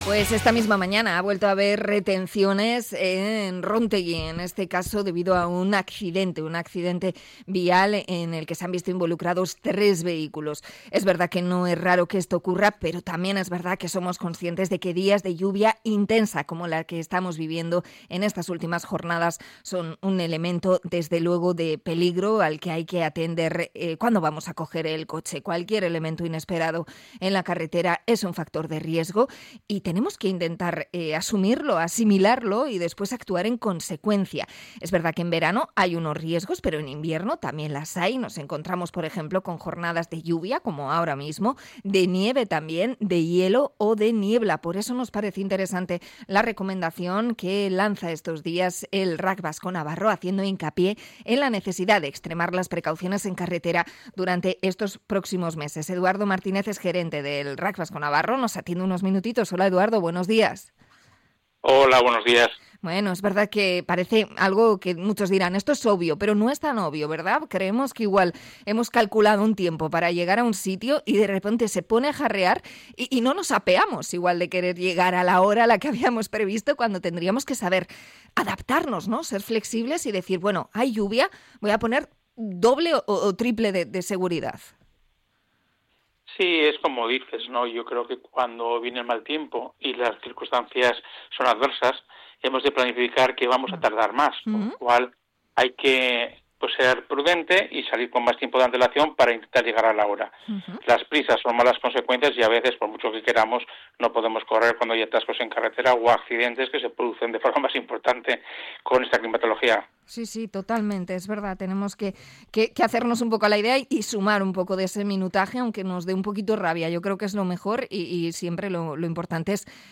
Entrevista a RACVN por la conducción invernal